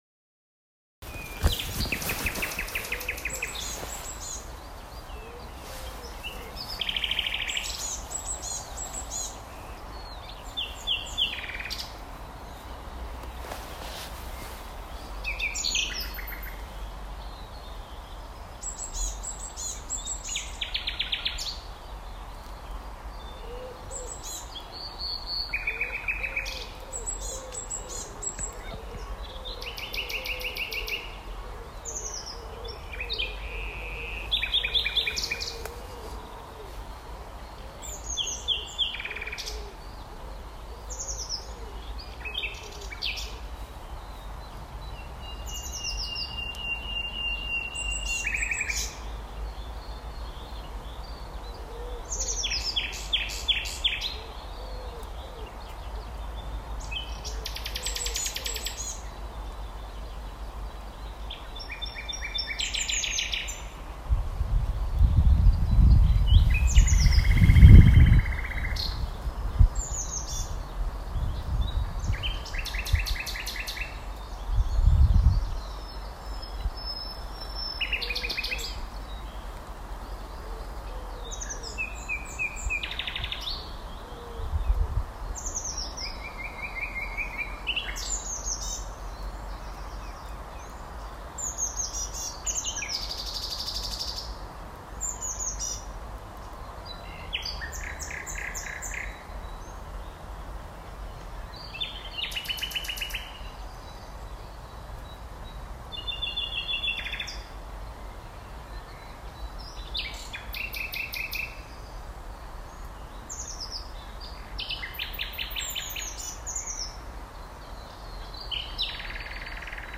Sydnäktergal Ljudinspelning 220513, Starbo, Uppsala